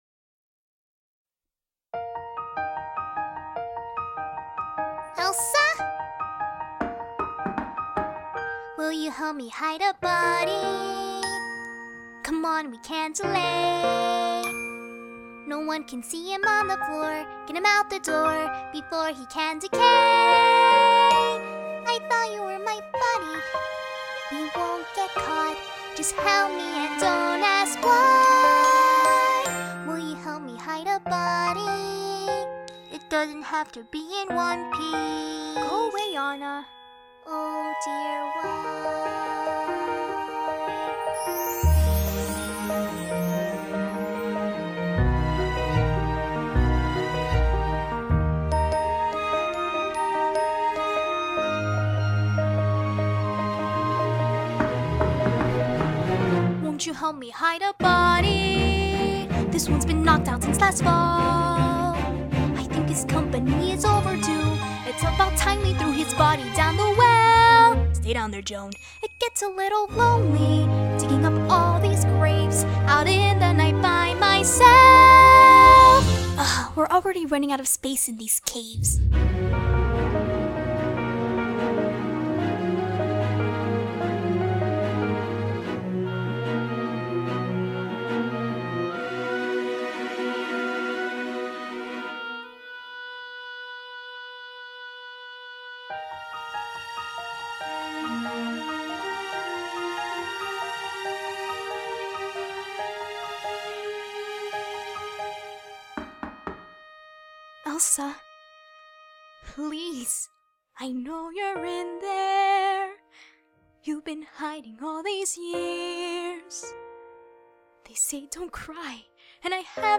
Still, we’re suckers for a good song parody.